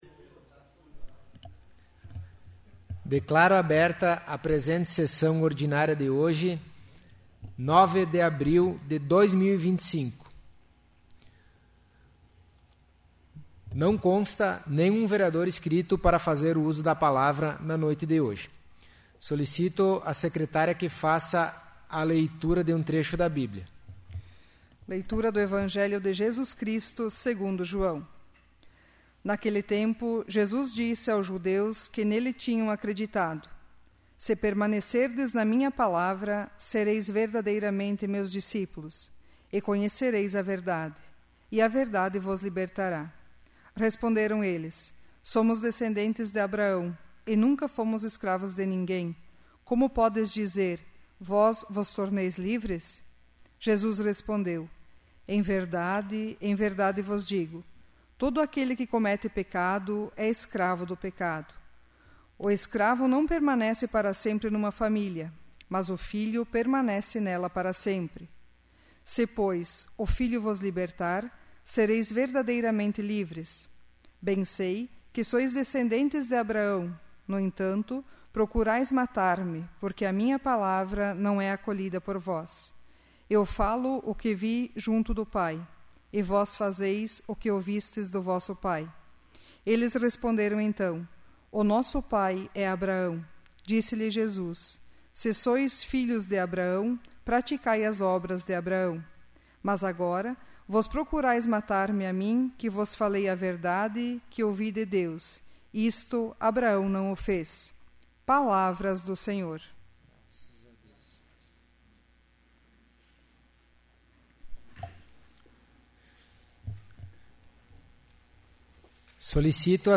'áudio da sessão do dia 14/05/2025'